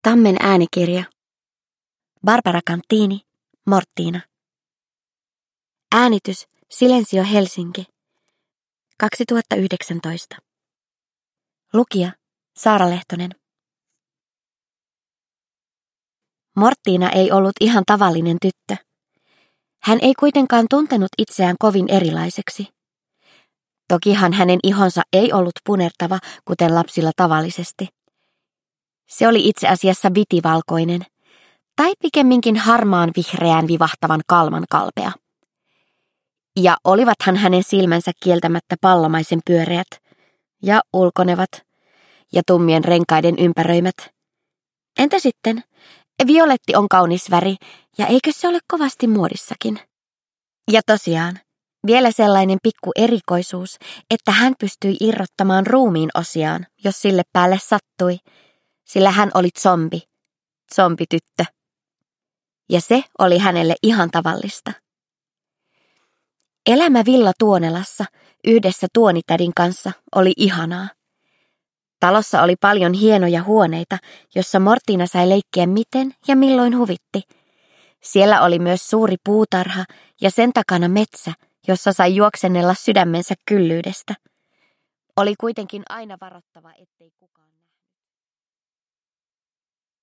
Mortina – Ljudbok – Laddas ner